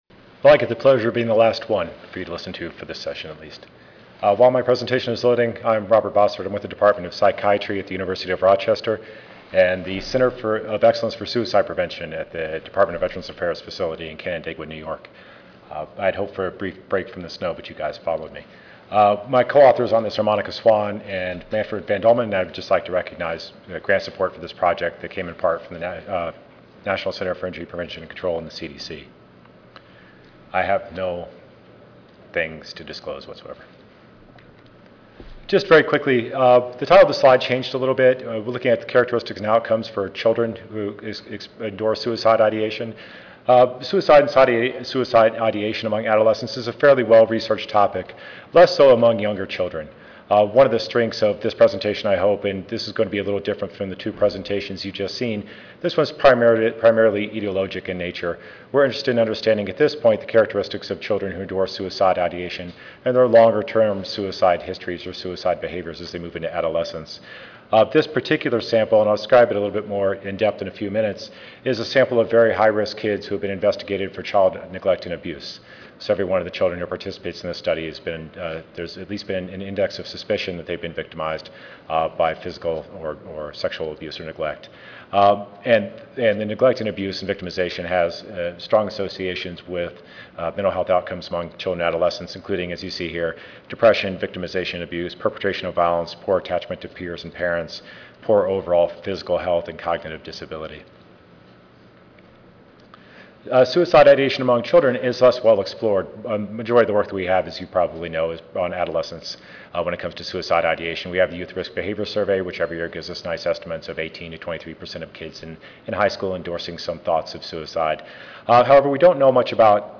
4299.0 Children's mental health: Treatment and use Tuesday, November 9, 2010: 2:30 PM - 4:00 PM Oral This session describes challenges in diagnosis and treatment of children�s mental health needs and strategies to improve children�s mental health service use.